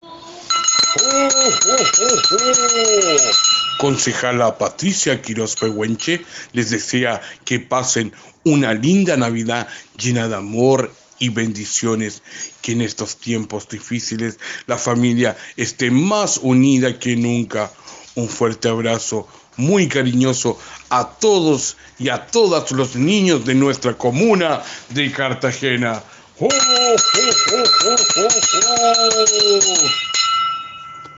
Un lindo saludo de la concejala Patricia Quiroz Pehuenche, con el Viejo Pascuero con motivo de Navidad 2020.